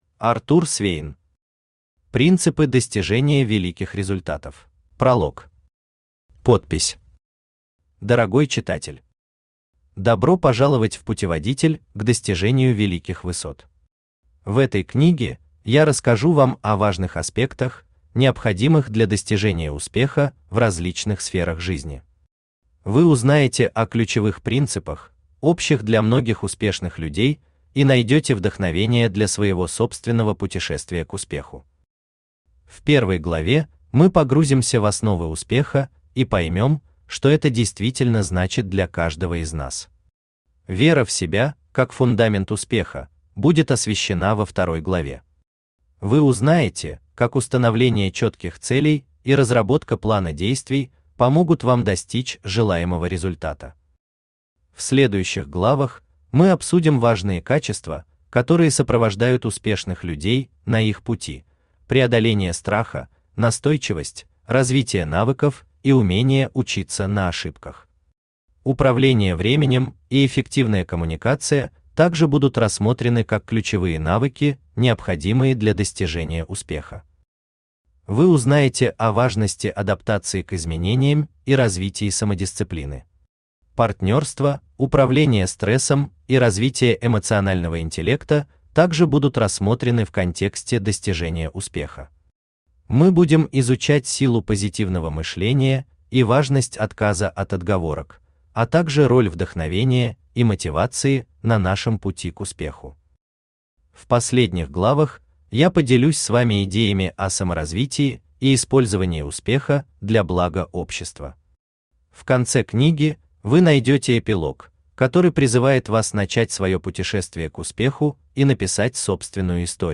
Аудиокнига Принципы достижения великих результатов | Библиотека аудиокниг
Aудиокнига Принципы достижения великих результатов Автор Артур Свейн Читает аудиокнигу Авточтец ЛитРес.